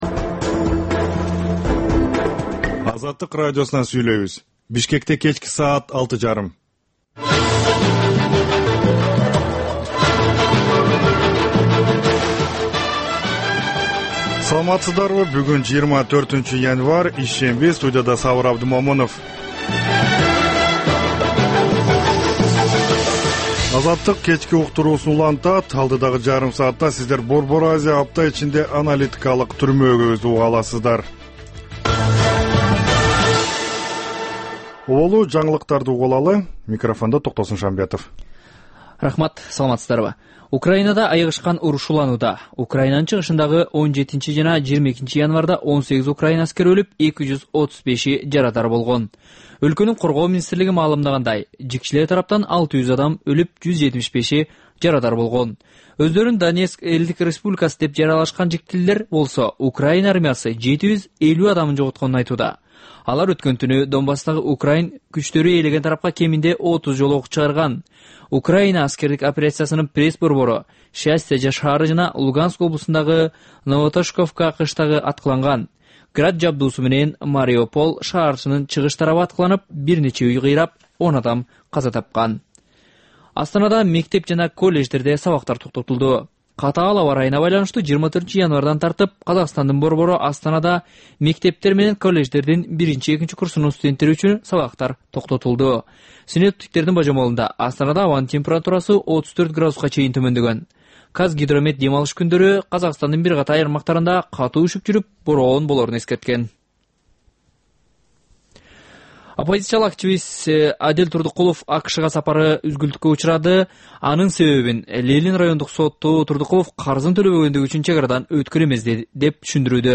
"Азаттык үналгысынын" бул кечки жарым сааттык экинчи берүүсү «Арай көз чарай» түрмөгүнүн алкагындагы тегерек үстөл баарлашуусу, репортаж, маек, талкуу, аналитикалык баян, сереп, угармандардын ой-пикирлери, окурмандардын э-кат аркылуу келген пикирлеринин жалпыламасы жана башка берүүлөрдөн турат. Бул үналгы берүү ар күнү Бишкек убакыты боюнча саат 18:30ден 19:00га чейин обого түз чыгат.